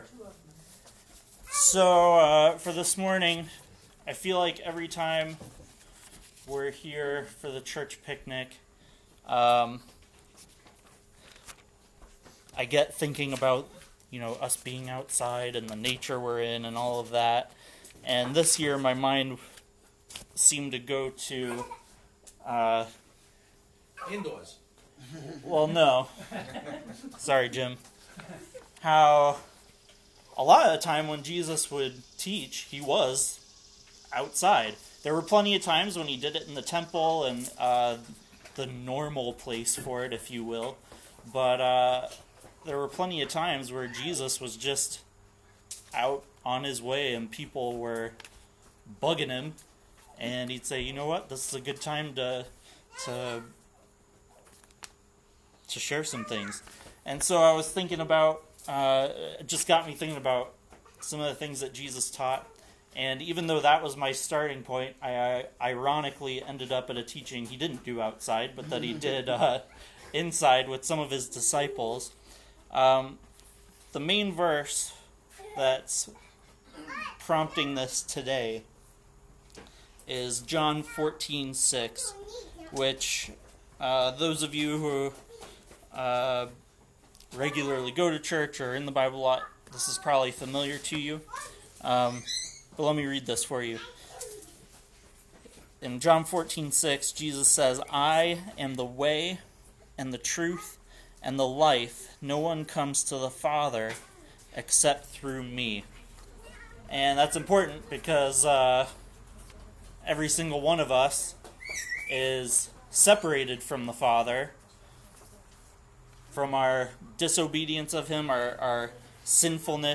Given at our annual church picnic, this message uses John 14:6 as a backdrop to consider some myths about what it means to be a Christian.